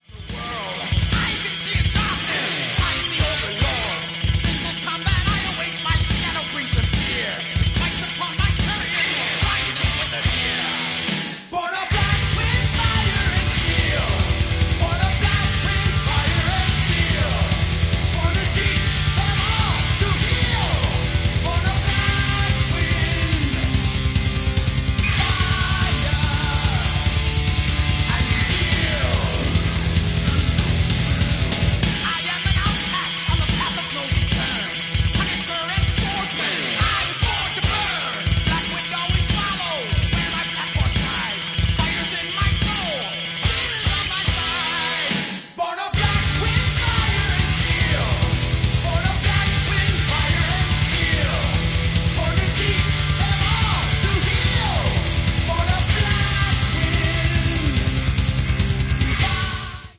heavy metal
four- and eight-string bass
drums Orson Welles